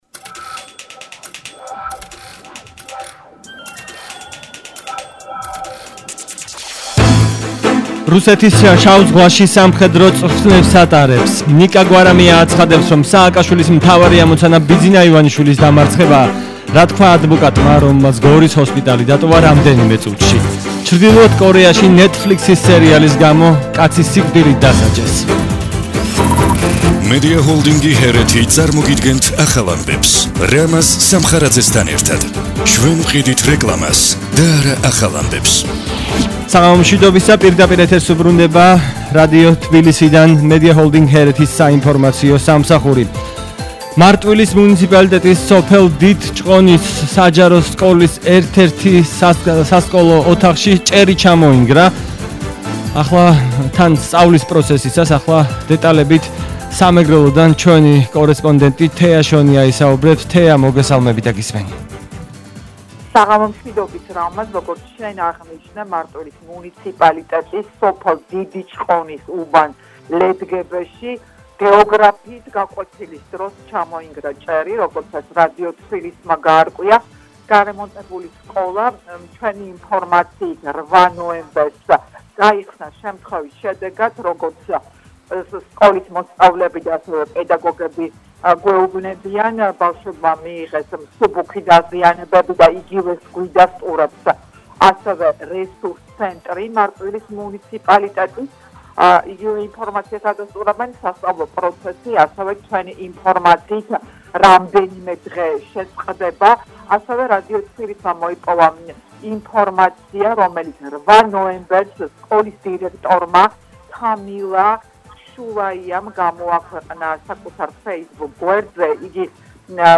ახალი ამბები 20:00 საათზე –24/11/21